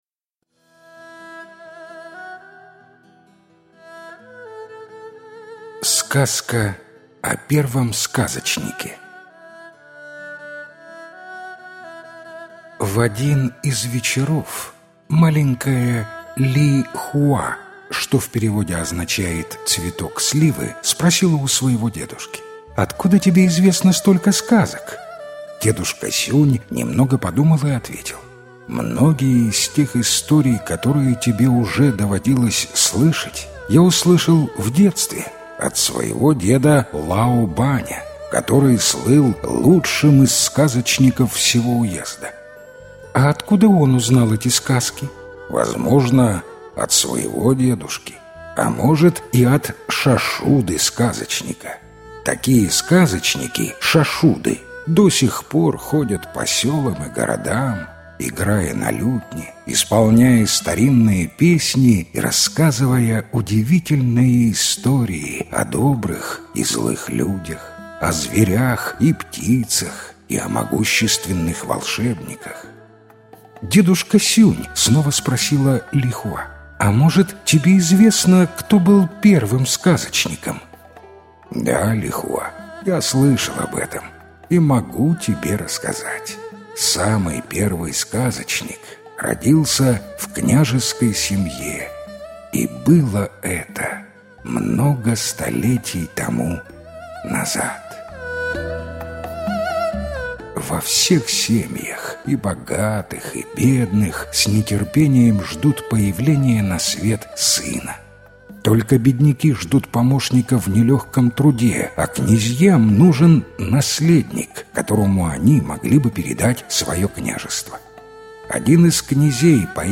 Сказка о первом сказочнике - китайская аудиосказка. Сказка про сына князя, родившегося слепым.